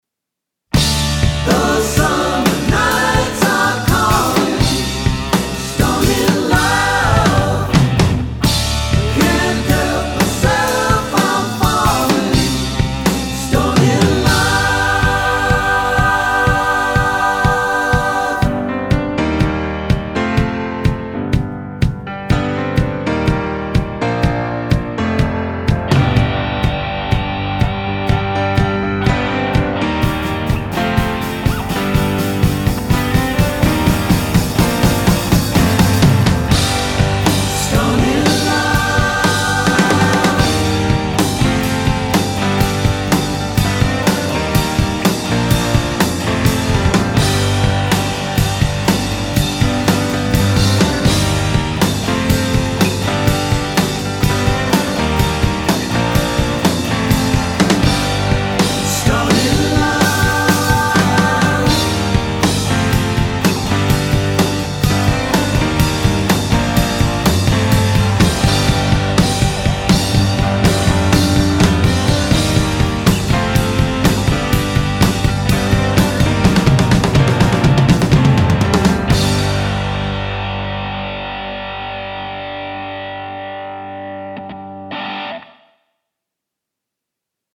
I track drums from start to finish without fixes.
TRIBUTE BAND